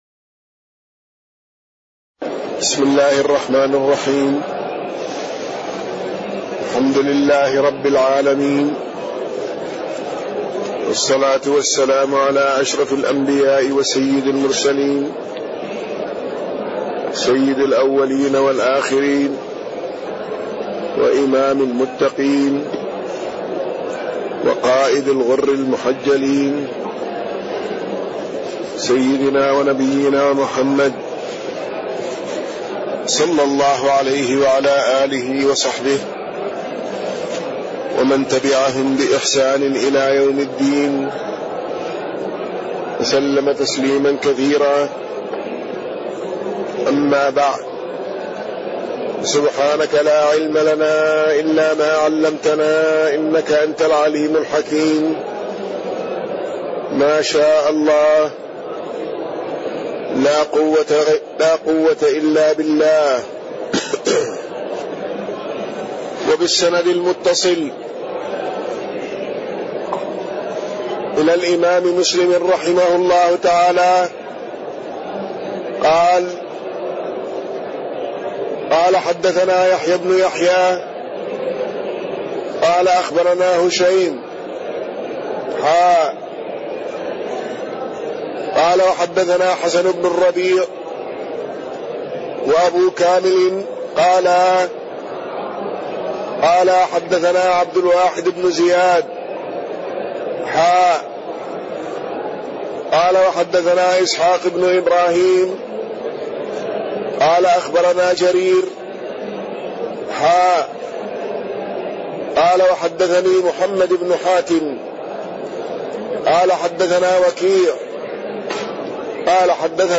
تاريخ النشر ٣ ربيع الثاني ١٤٣٢ هـ المكان: المسجد النبوي الشيخ